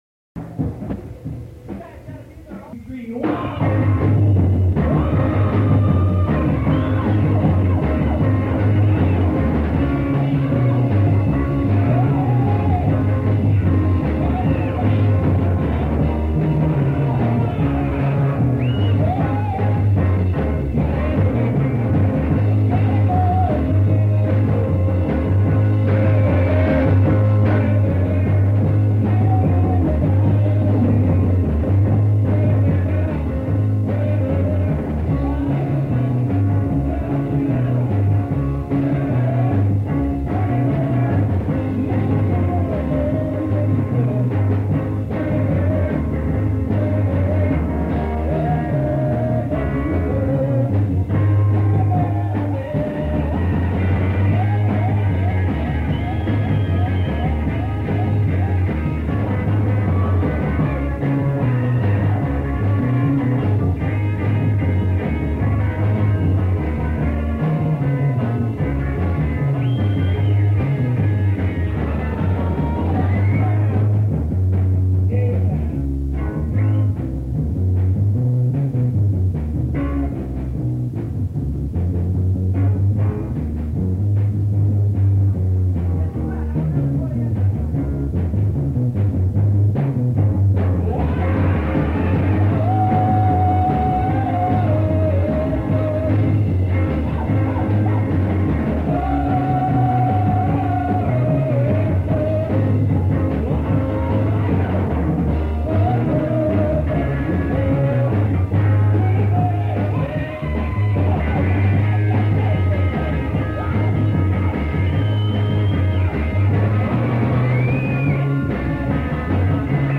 recorded live back in around 1965 by a Rochester